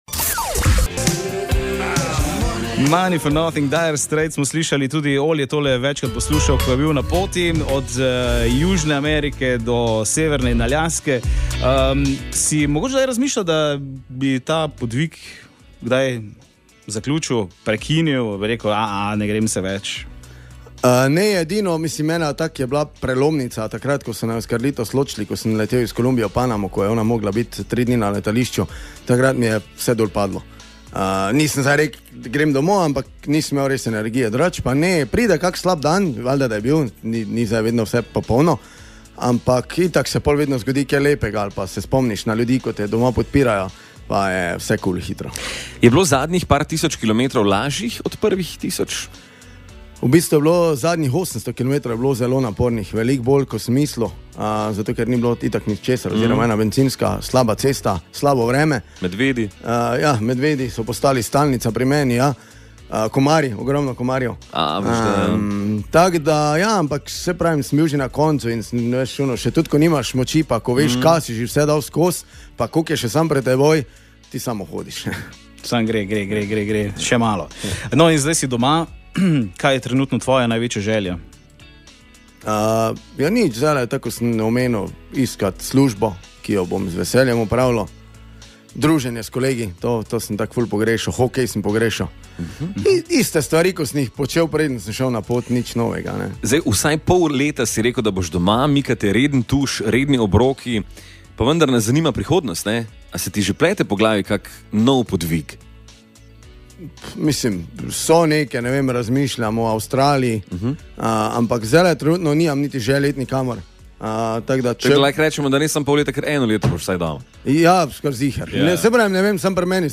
Z neverjetnima popotnikoma smo preživeli ponedeljkovo jutro na Radiu Rogla.